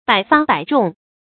注音：ㄅㄞˇ ㄈㄚ ㄅㄞˇ ㄓㄨㄙˋ
百發百中的讀法